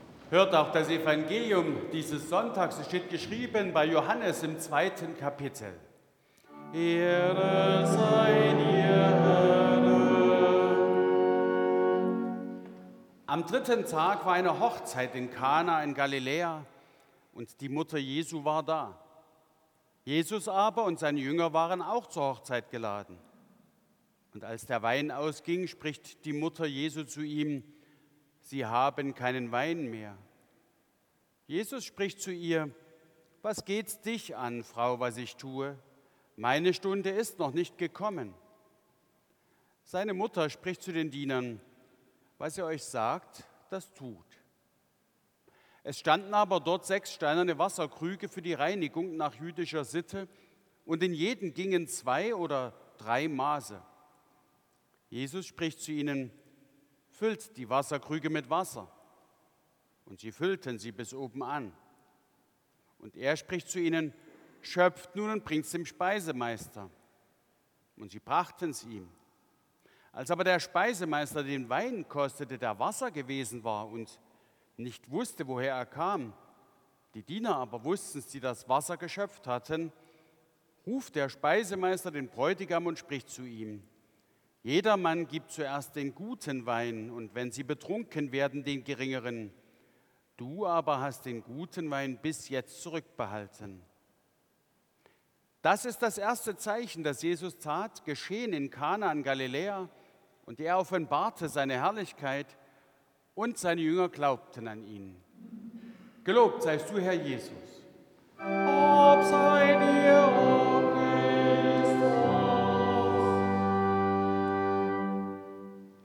7. Evangeliumslesung aus Johannes 2,1-11 Ev.-Luth.
Audiomitschnitt unseres Gottesdienstes vom 2. Sonntag nach Epipanias 2026.